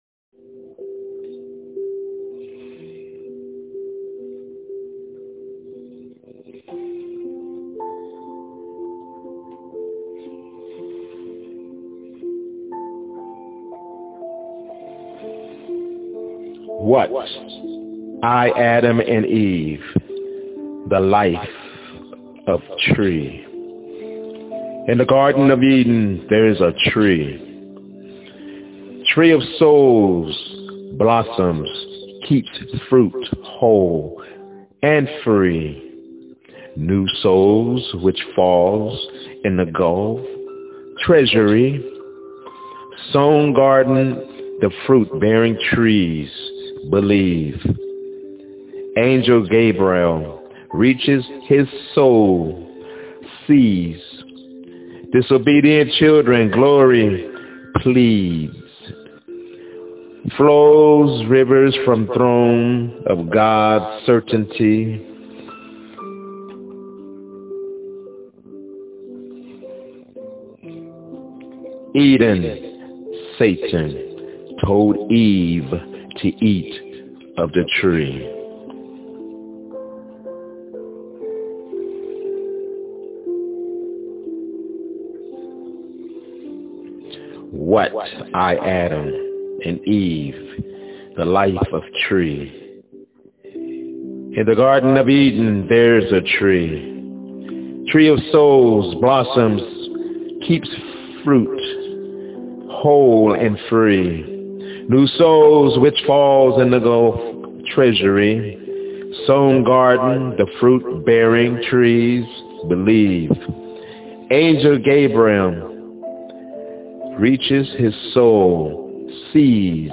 Tree of Fruit Don'T Eat-My Spokenword-